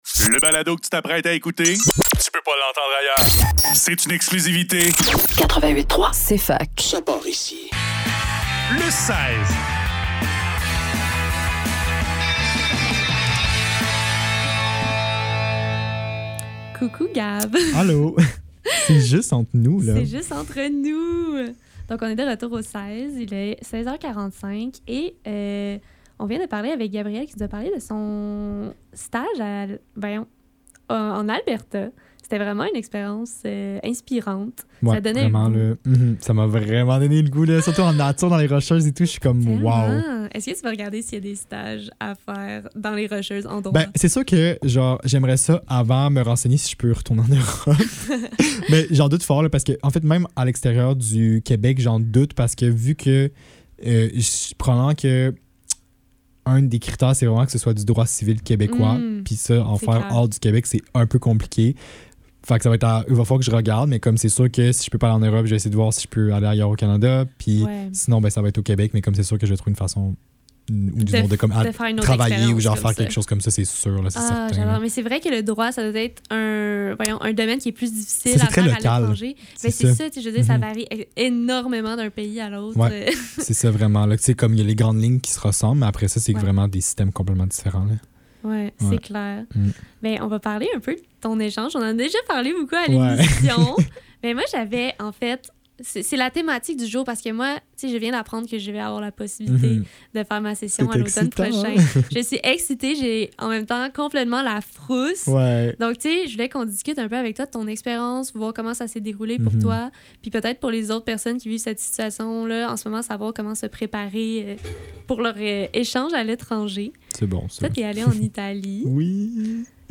Le seize - Faire une session à l'étranger, à quoi s'attendre ? Entrevue